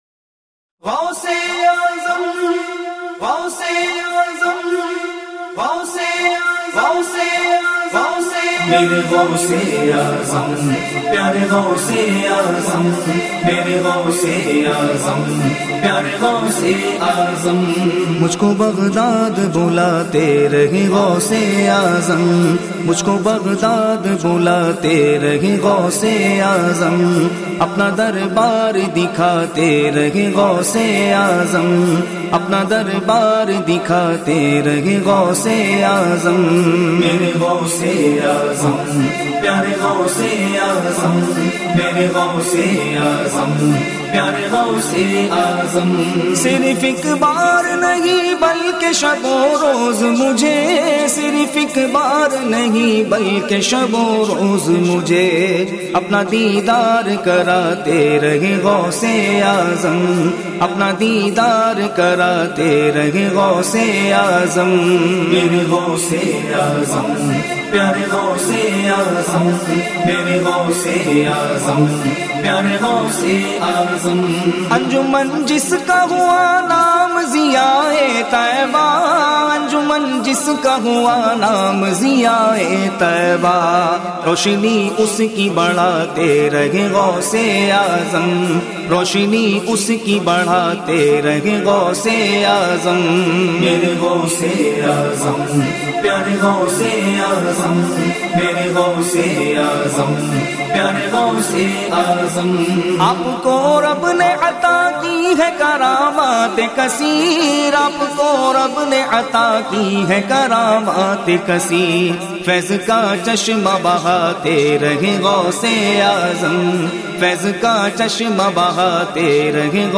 An Istighasa / Manqabat